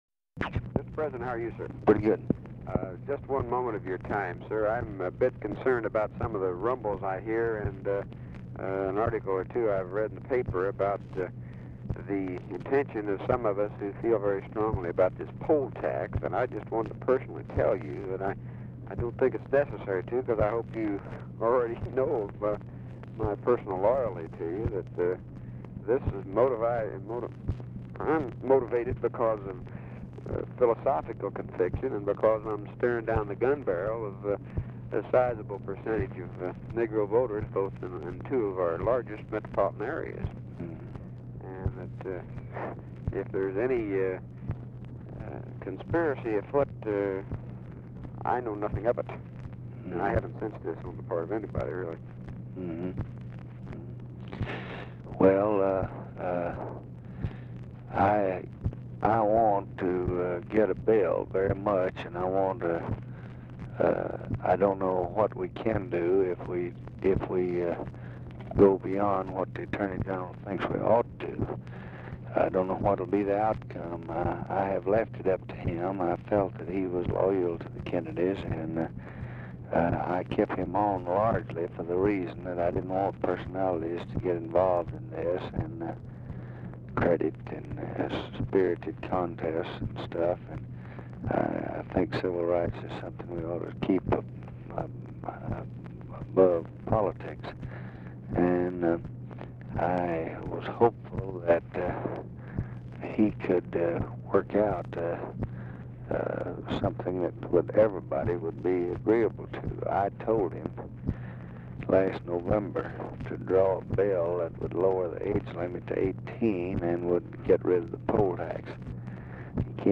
Telephone conversation # 7603, sound recording, LBJ and BIRCH BAYH, 5/7/1965, 4:45PM | Discover LBJ
Format Dictation belt
Location Of Speaker 1 Mansion, White House, Washington, DC